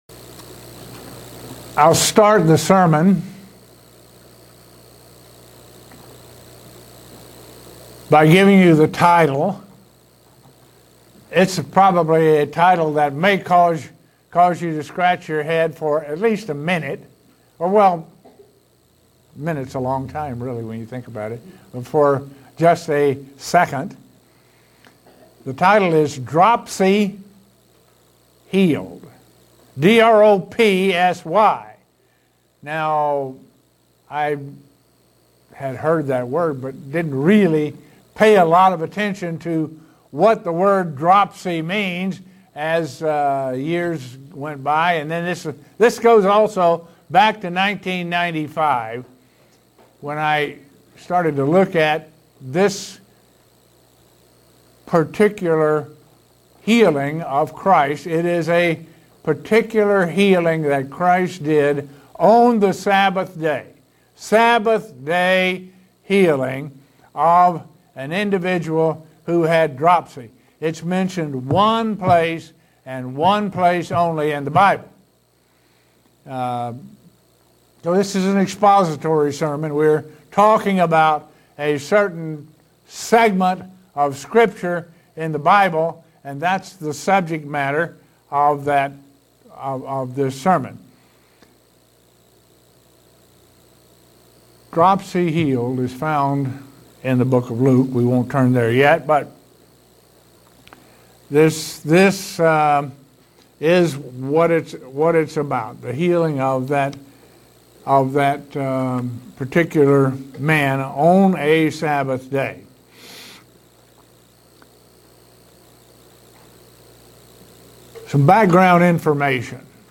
Given in Buffalo, NY
Understanding how its prophetic and what it means to us. sermon Studying the bible?